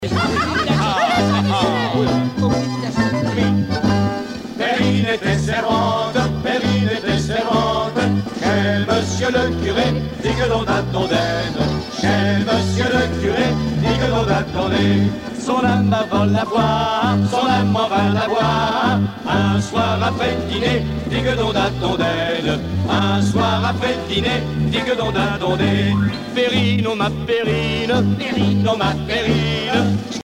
Fonction d'après l'analyste danse : marche
Genre laisse